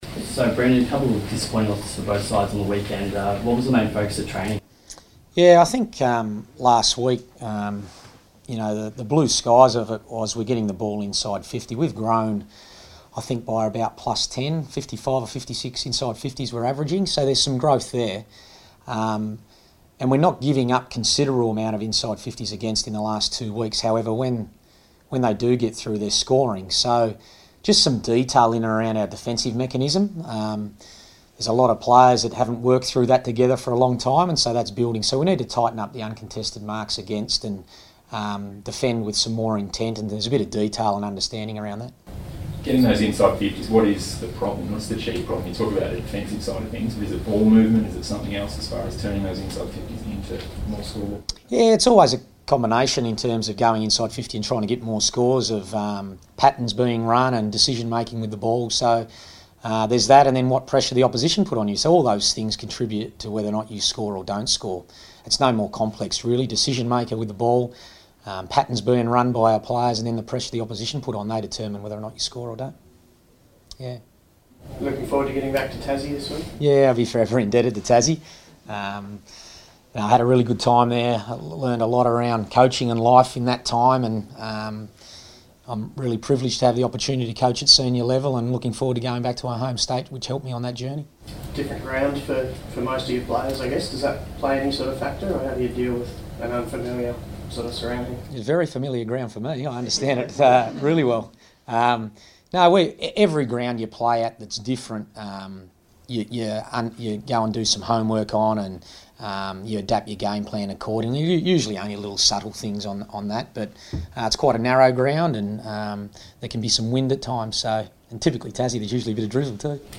Brendon Bolton press conference | April 12
Carlton coach Brendon Bolton fronts the media ahead of the Blues' Round 4 clash with North Melbourne at Blundstone Arena.